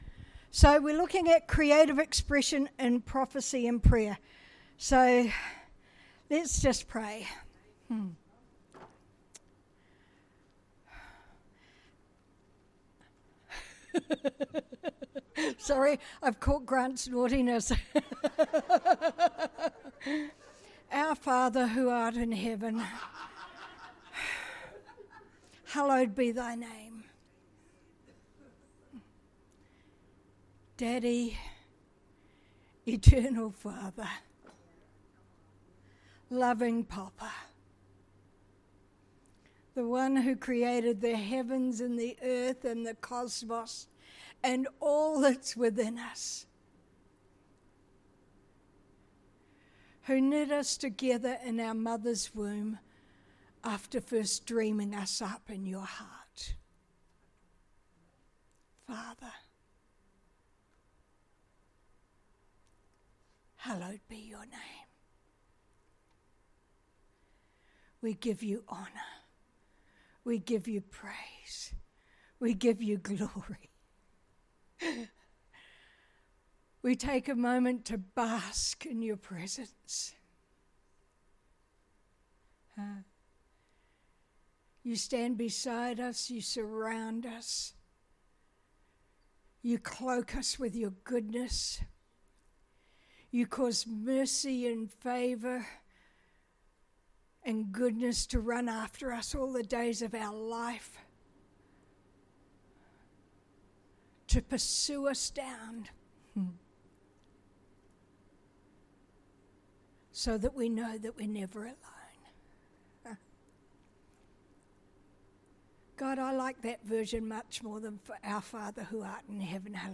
Sermons | Living Waters Christian Centre